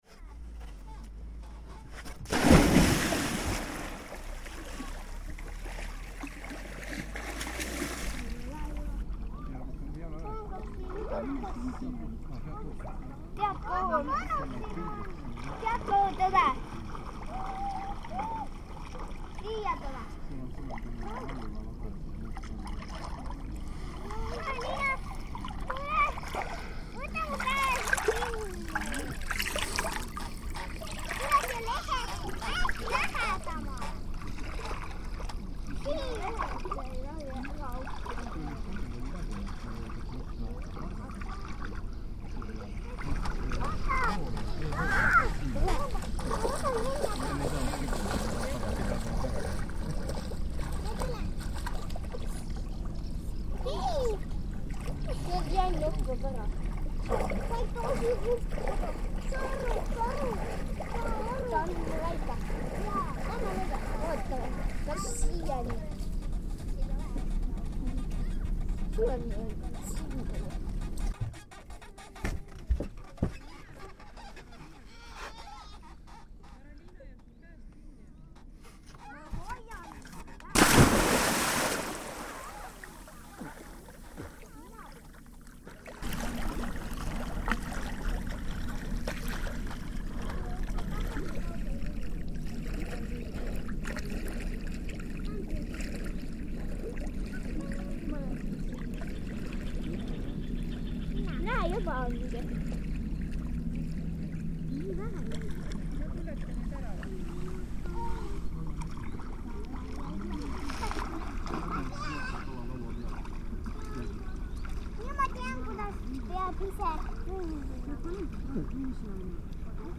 I wanted to record some local sounds and went to the swimming place at the lake nearby.I asked the people who were picnicking by the lake if they thought it would be ok to record and they said yes. I sat on the pier.